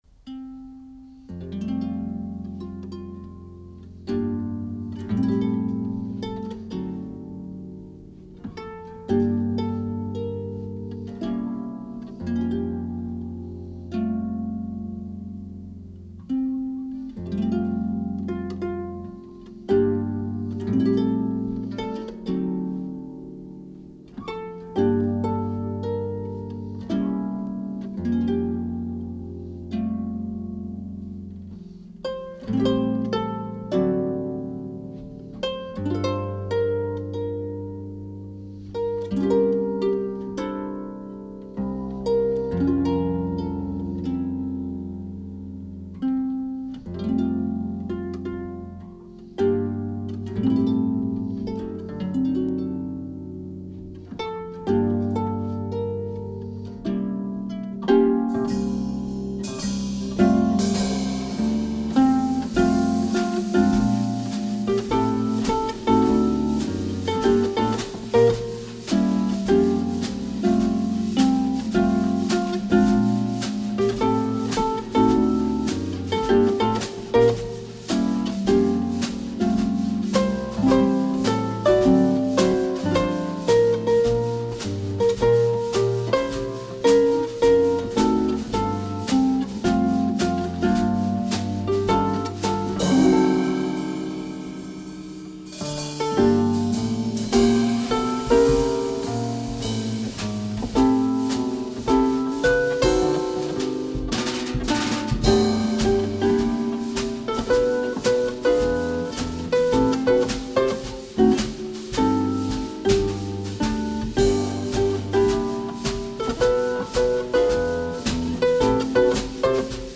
harp
drums